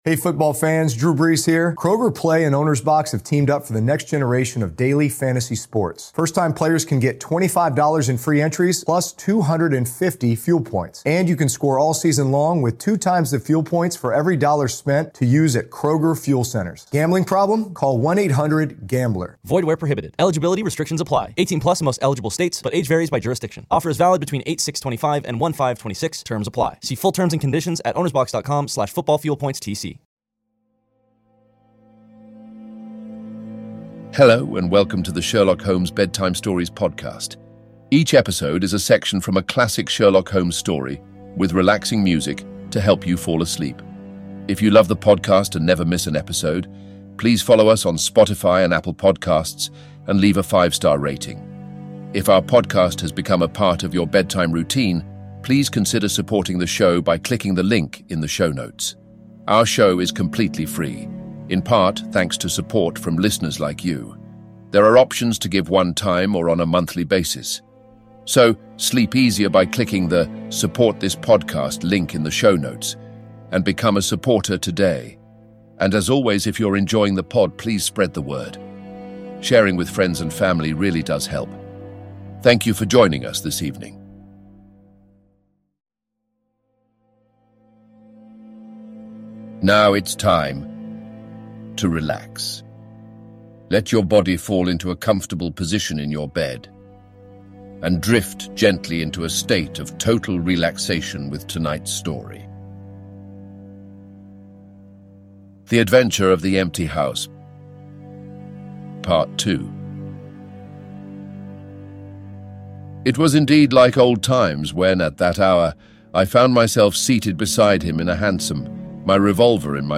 Each episode is a section of a classic Sherlock Holmes story, read in soothing tones and set to calming music to help you fall asleep.